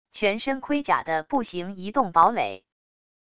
全身盔甲的步行移动堡垒wav音频